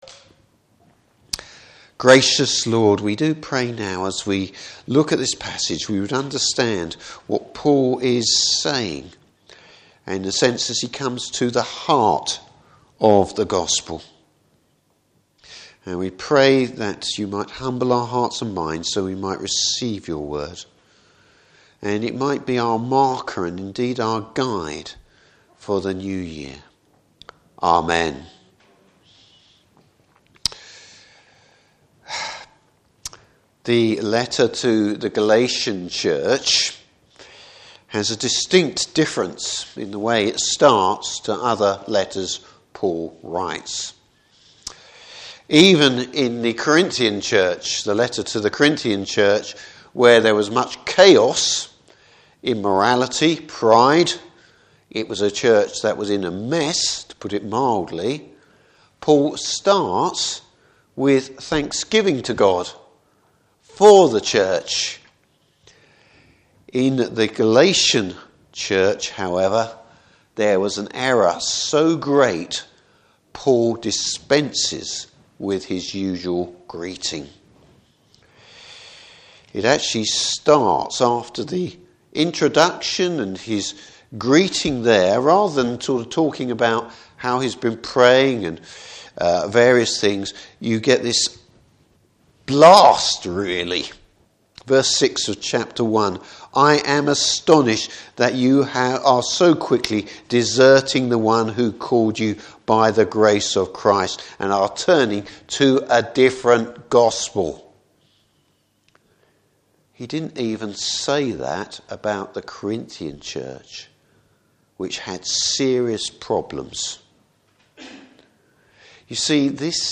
Service Type: Evening Service God’s prefect timing.